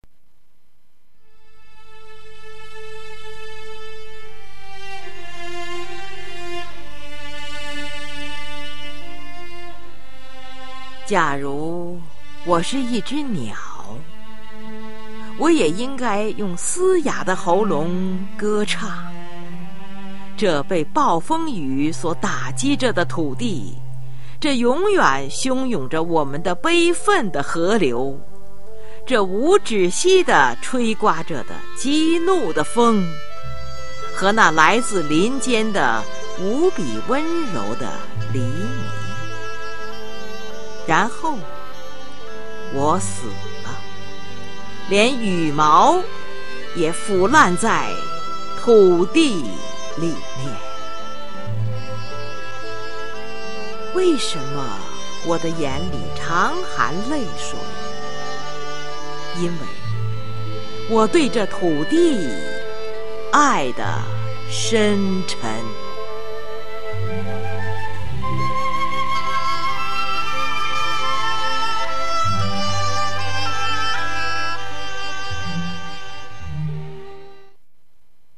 [15/2/2014]中央人民广播电台播音大师林如朗诵《我爱这土地》